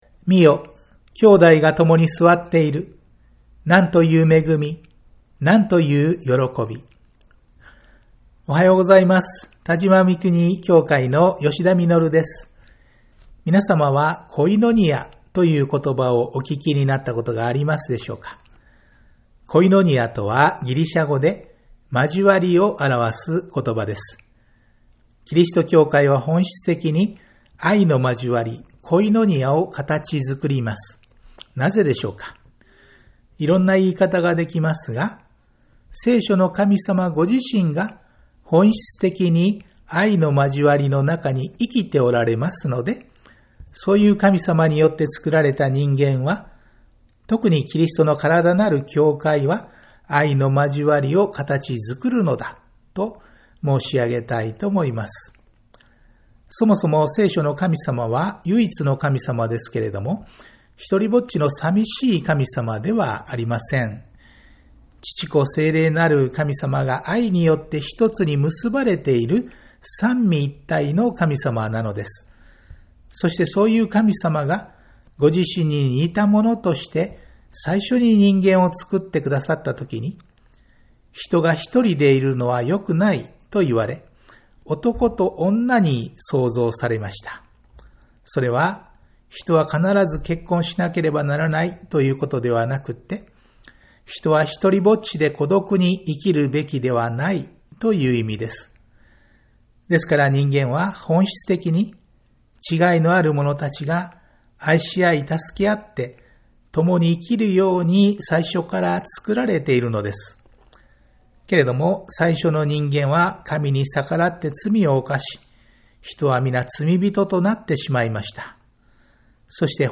ラジオ番組「キリストへの時間」